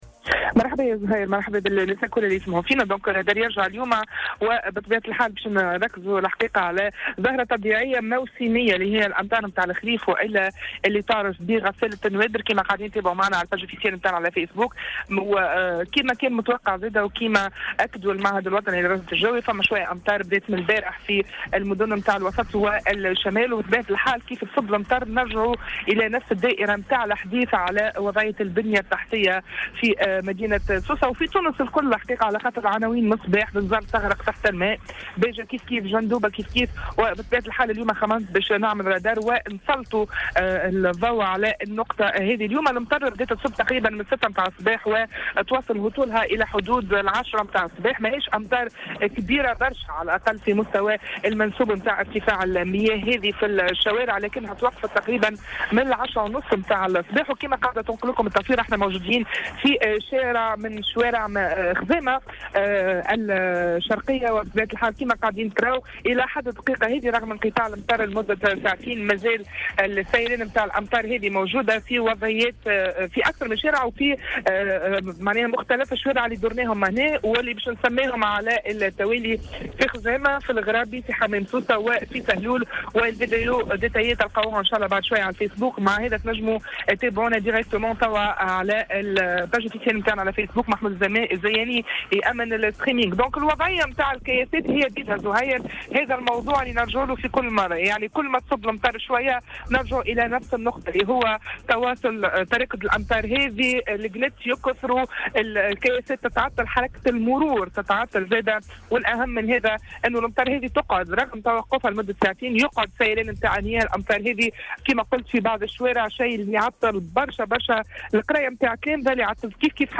تنقل فريق "الرادار" اليوم الاثنين 02 أكتوبر 2017، إلى أنهج وشوارع في منطقة خزامة، لمعاينة حالتها بعد نزول الأمطار التي شهدتها ولاية سوسة.